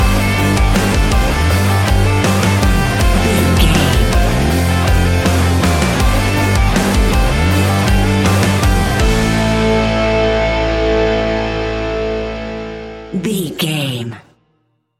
Ionian/Major
A♭
hard rock
heavy metal